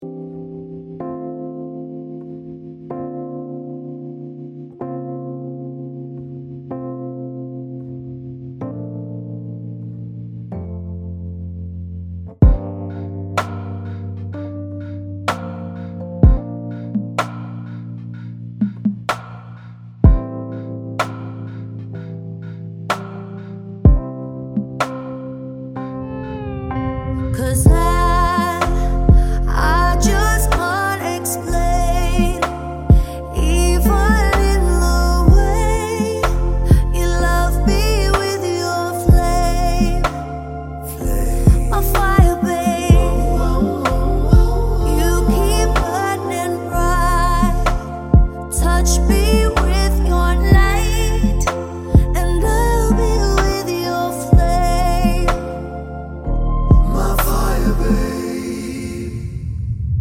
no Backing Vocals R'n'B / Hip Hop 3:41 Buy £1.50